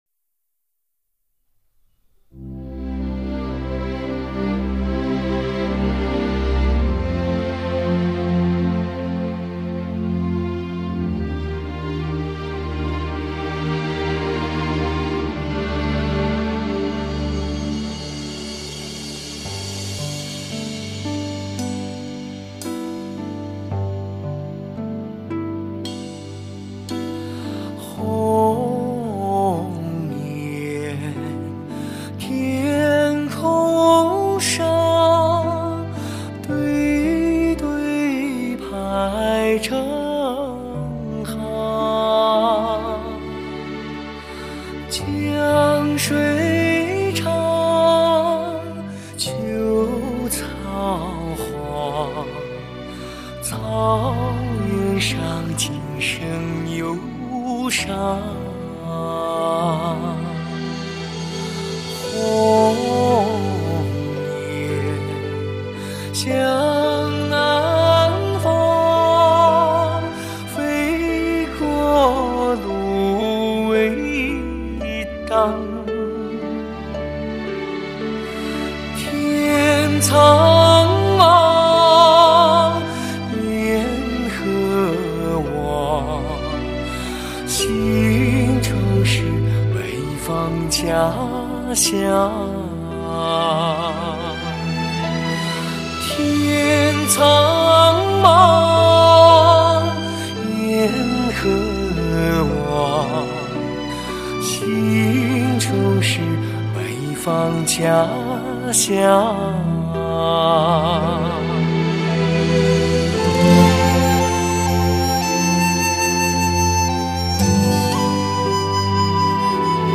优美动听的声线诠释一种无暇的音乐境界